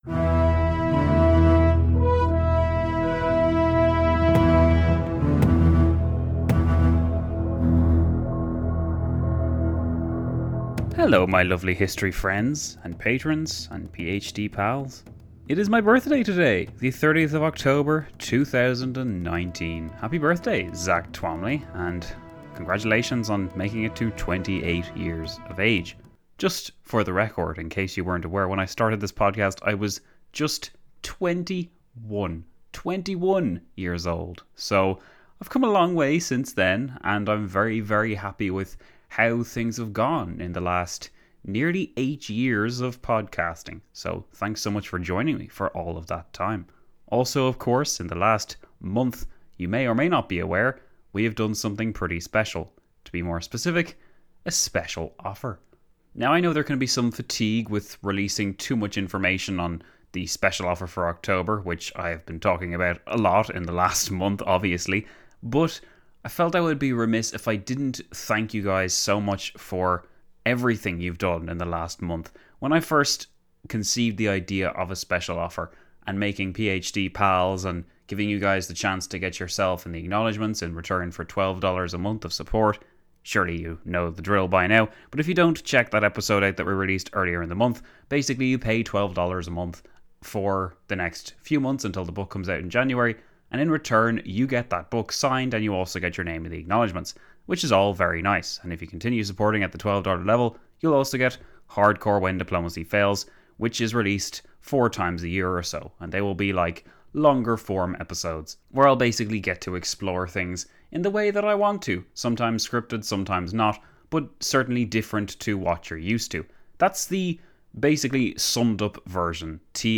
[PATRONS] In an unscripted romp through several issues, I say thanksss for joining me and for making October 2019 our most successful month yet!